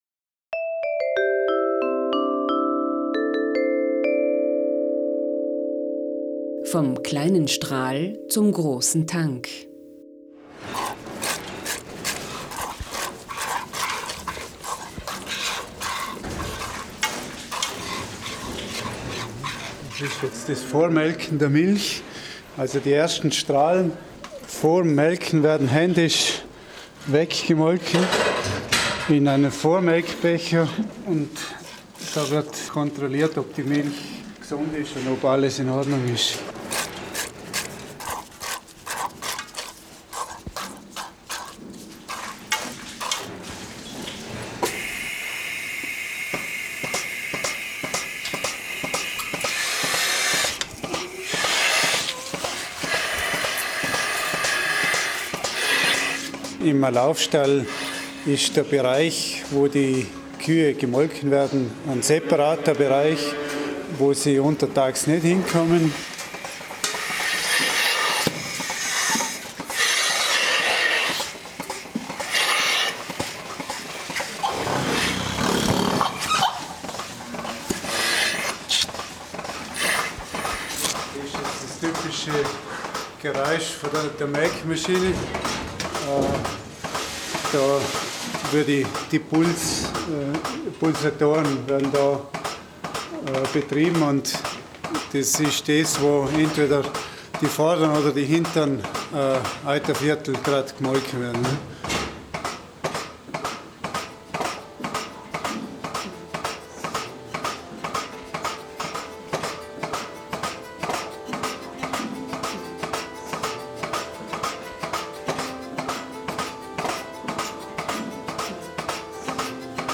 Menschen aus Lech erzählen, welche Geräusche sie in ihrem Alltag begleiten und welche Töne in ihrer Biografie eine Rolle gespielt haben.
Ihre Erzählungen und die damit assoziierten Geräusche verbinden sich mit den eigens dazu komponierten und eingespielten Musikfragmenten zu neun sehr unterschiedlichen Klangreisen in vergangene und gegenwärtige Welten.